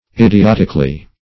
Idiotically \Id`i*ot"ic*al*ly\, adv.